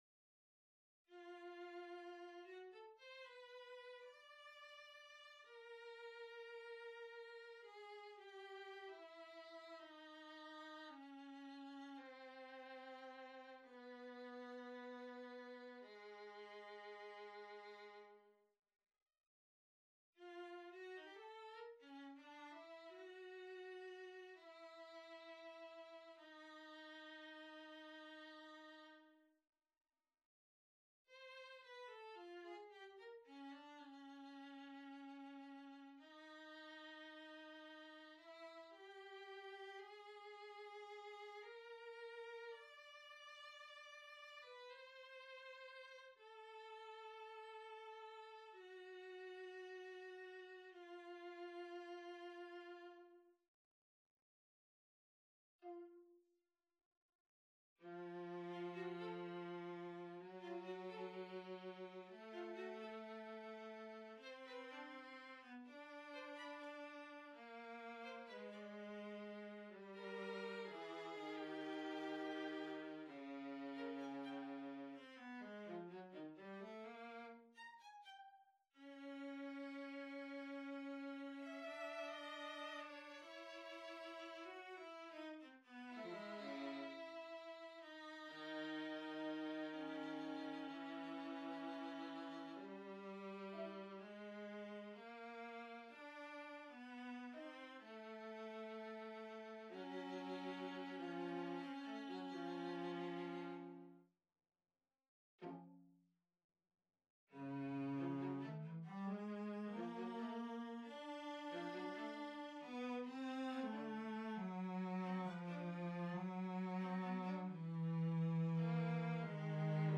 Trio for Strings No.3 on a purpose-selected tone row Op.63 1. Largo pensosamente - Larghetto - Adagio - Adagietto - Andante - Tempo I - Allegretto - Allegro - Andante 2.
Lento molto - Meno mosso - Poco meno mosso - Tempo I 4.